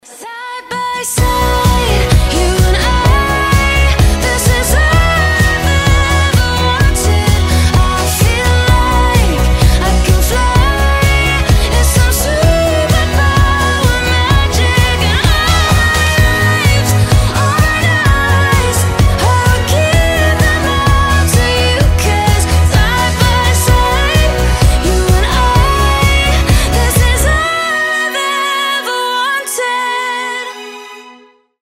• Качество: 320, Stereo
красивый женский голос
вдохновляющие